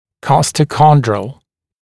[ˌkɔstə’kɔndrəl][ˌкостэ’кондрэл]реберно-хрящевой